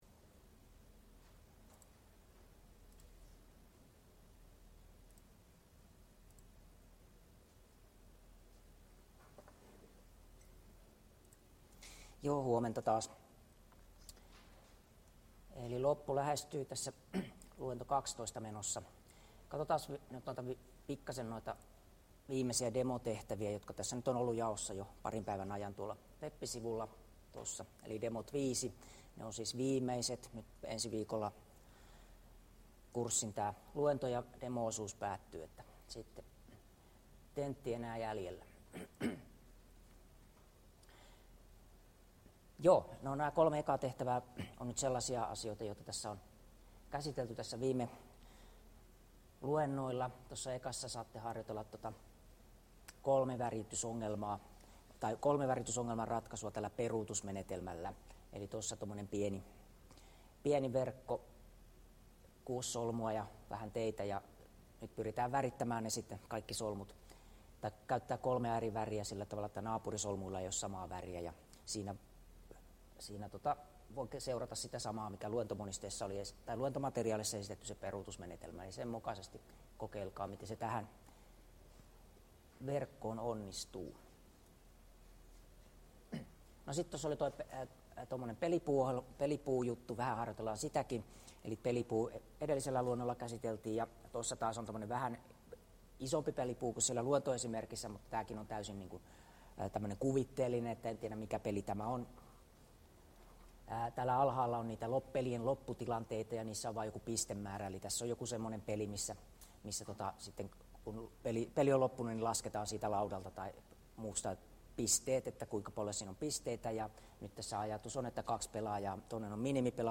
Luento 12 — Moniviestin